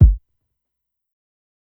KICK_SMITH.wav